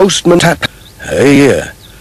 Play, download and share Uuhyeauh original sound button!!!!
ytp-postman-tap-delivers-fake-letters.mp3